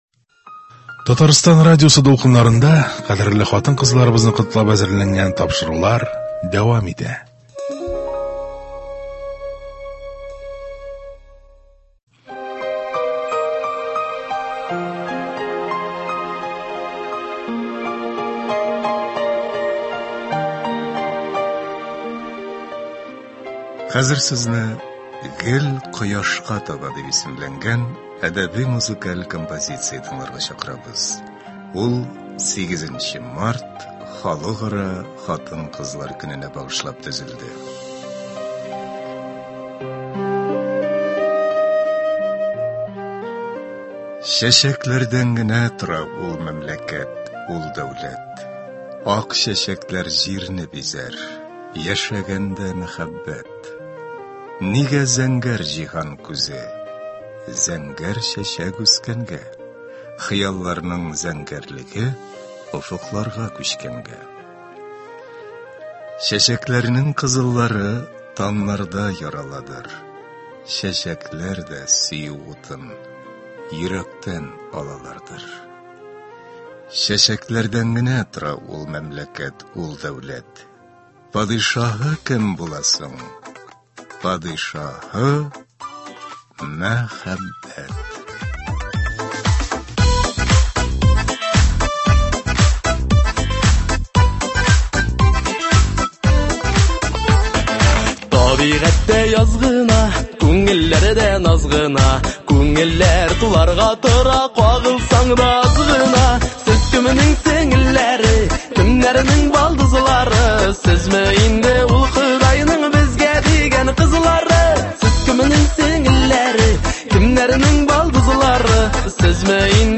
Сезне “Гел кояшка таба” дип исемләнгән әдәби-музыкаль композиция тыңларга чакырабыз. Ул 8 нче март – Халыкара хатын-кызлар көненә багышлап төзелде.